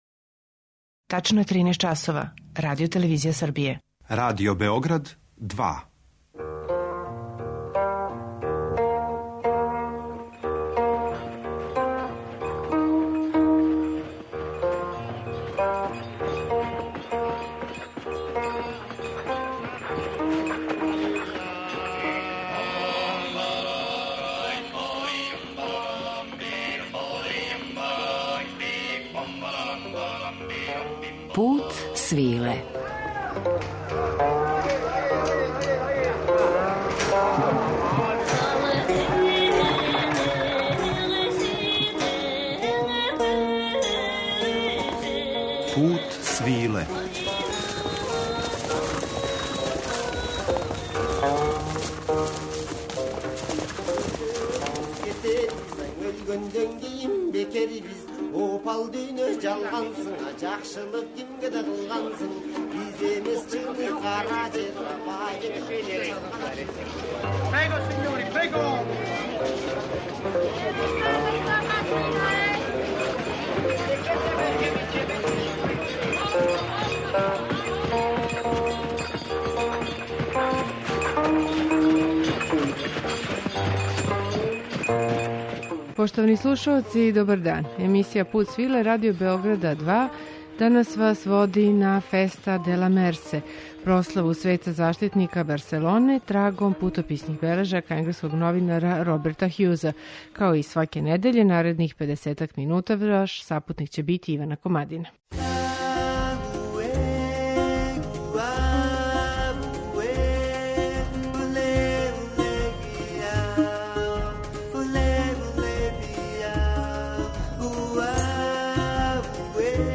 уз актуелна остварења из жанра “World music” и раритетне записе традиционалне музике.